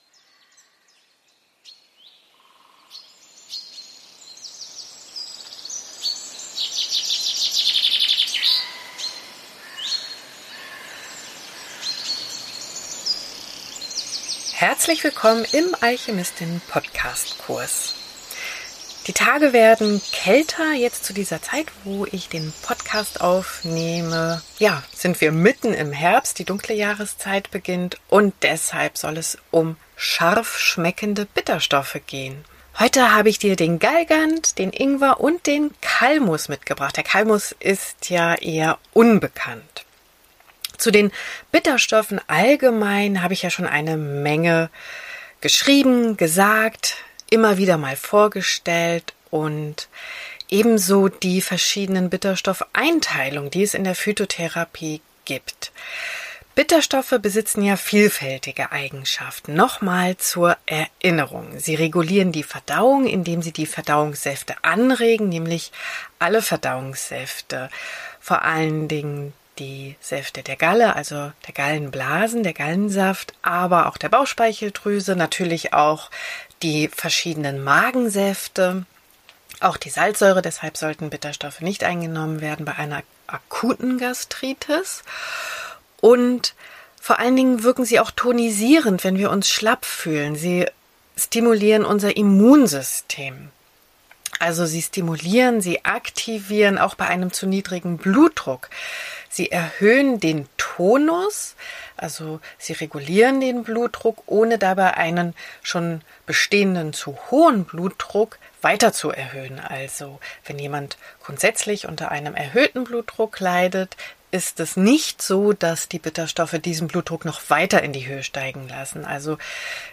Ein spannendes Interview